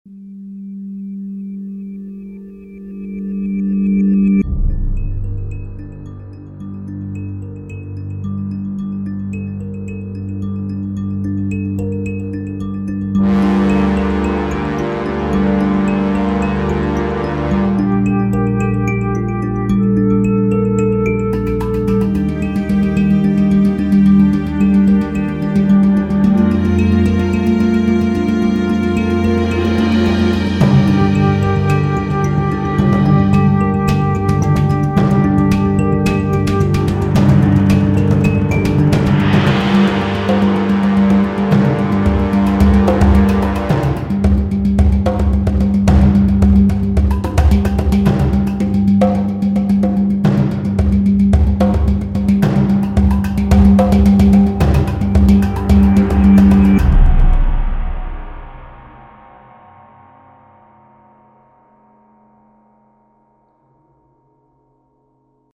Данная композиция наполнена слегка напряженной и меланхоличной атмосферой, подойдет для проекта с серьезным сценарием и атмосферой.